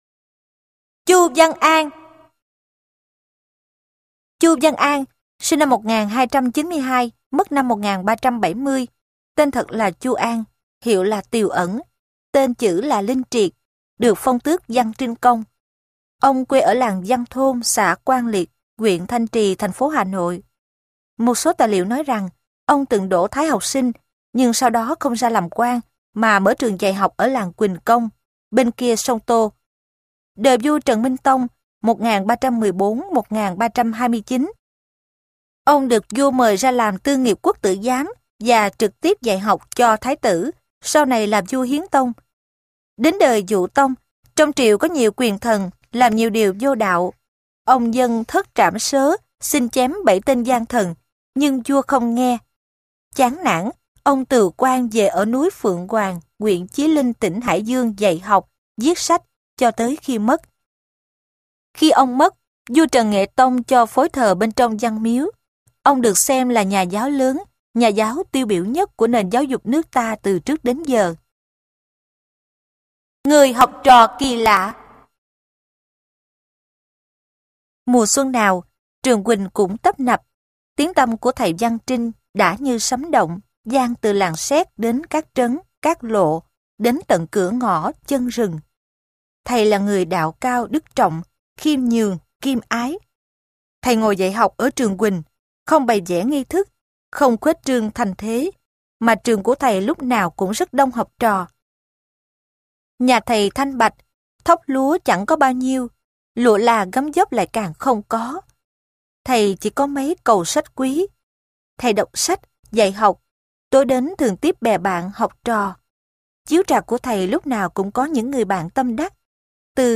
Sách nói Thời Cuối Nhà Trần - Ngô Văn Phú - Sách Nói Online Hay